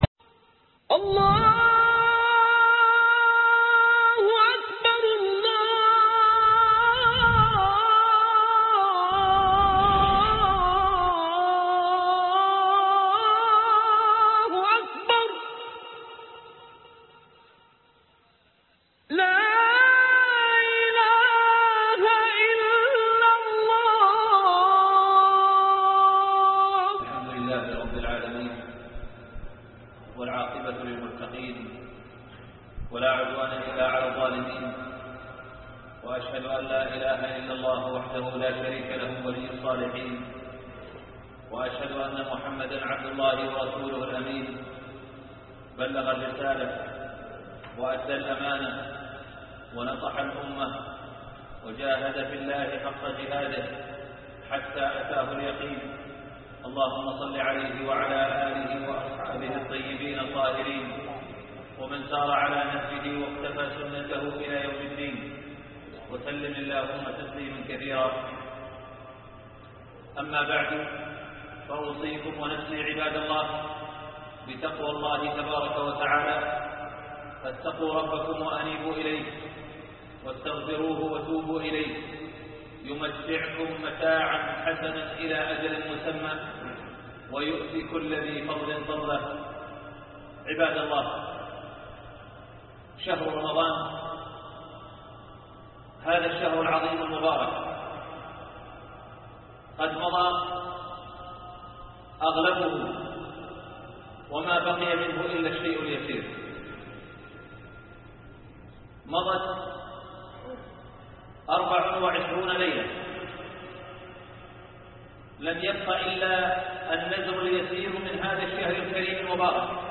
ليالى المغفرة ( خطب الجمعة